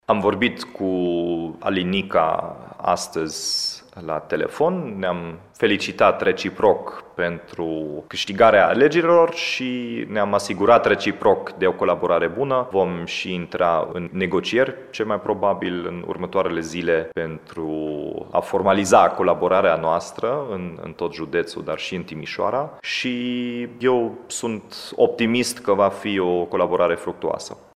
Anunțul a fost făcut pentru pentru Radio Timișoara de primarul ales, Dominic Fritz.